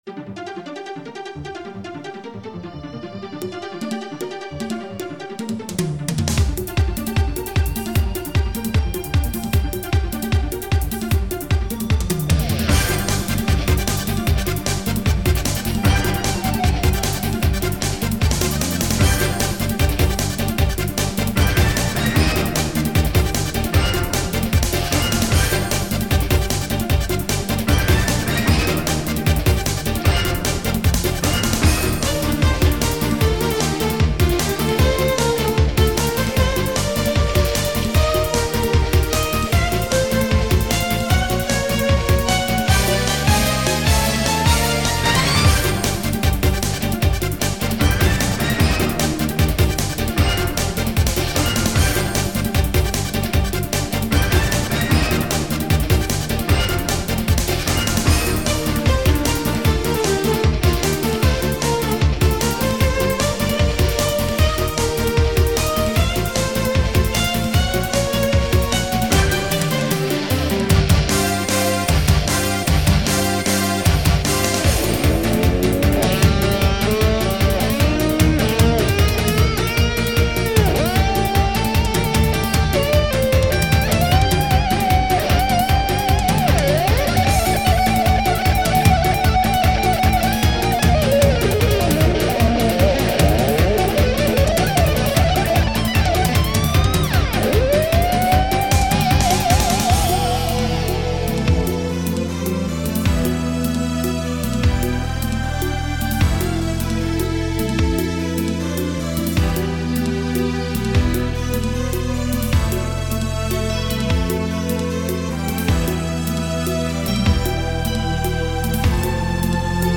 "rockin' out" type of game tune
arranged remake